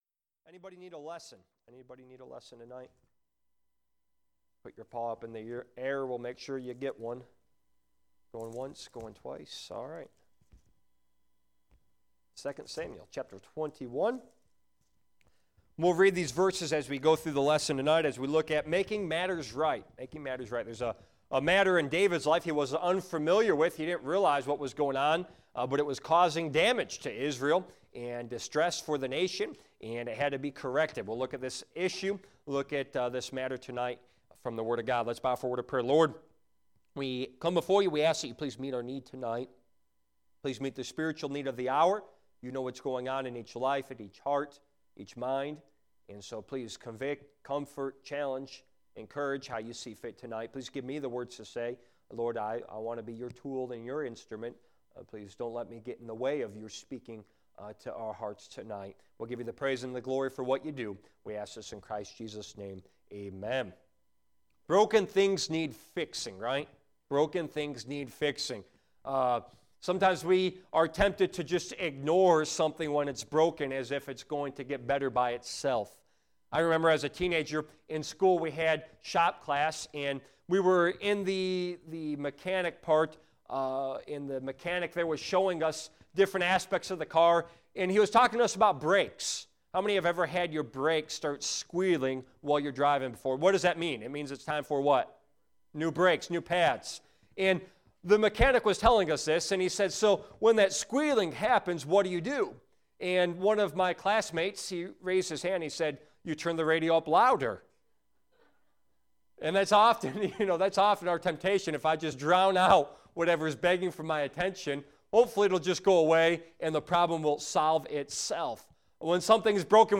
Genre: Bible Study.